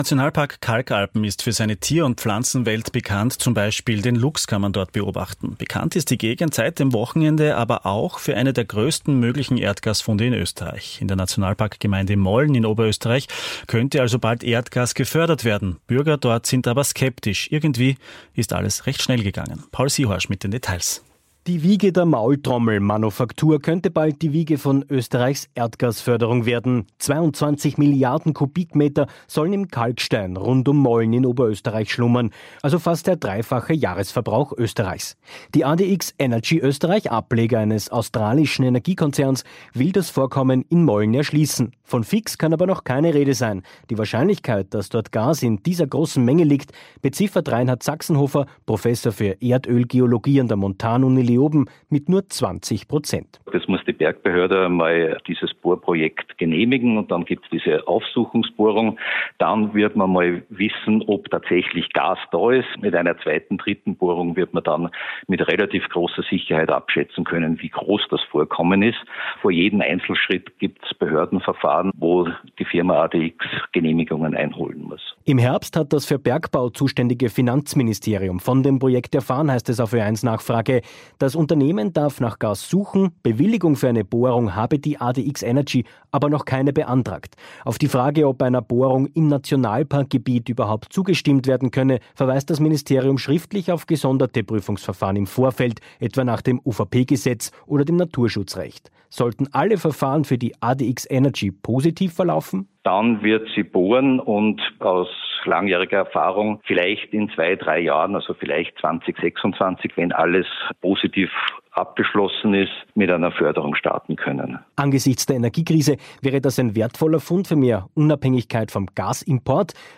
OE1 Radiobeitrag: